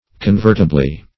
convertibly - definition of convertibly - synonyms, pronunciation, spelling from Free Dictionary Search Result for " convertibly" : The Collaborative International Dictionary of English v.0.48: Convertibly \Con*vert"i*bly\, adv.